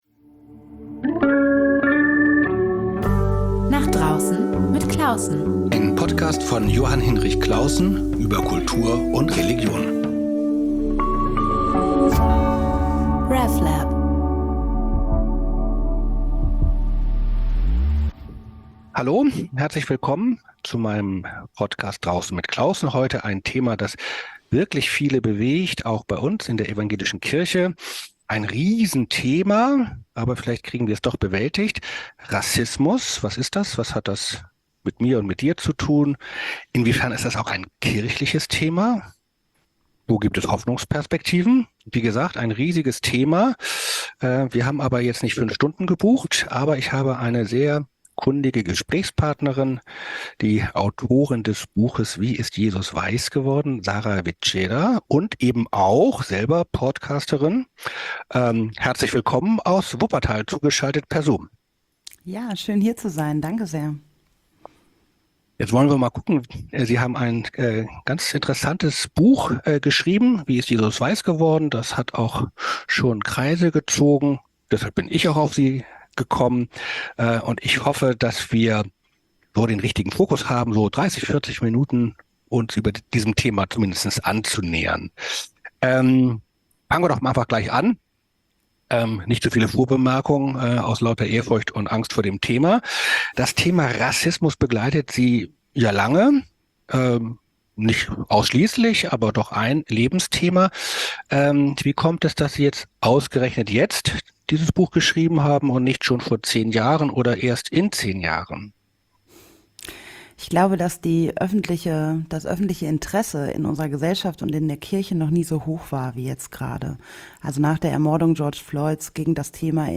Über überraschende kulturelle Entwicklungen, tolle neue Kunstwerke oder aktuelle Konflikte. Nicht als journalistisches Frage-Antwort-Spiel, sondern als gemeinsames, ernsthaft-unterhaltsames Nachdenken.